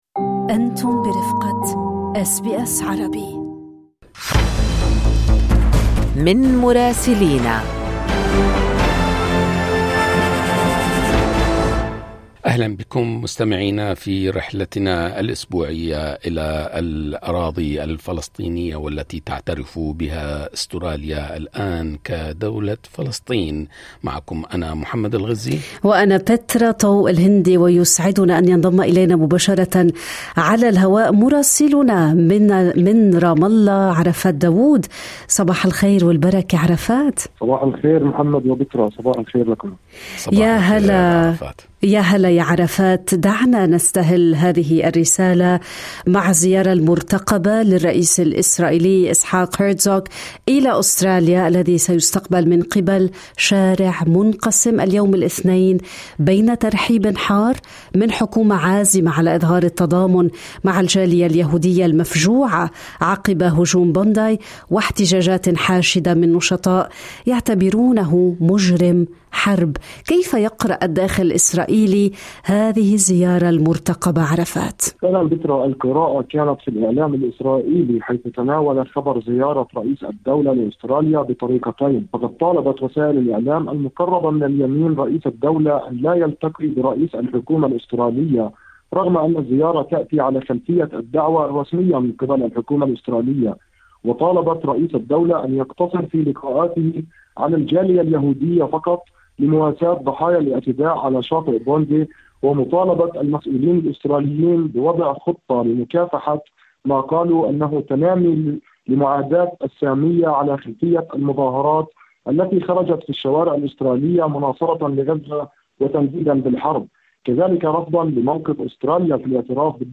من مراسلينا: هرتسوغ في أستراليا وسط احتجاجات وانقسامات، وخروقات غزة تتواصل [AI Ad: Arabic]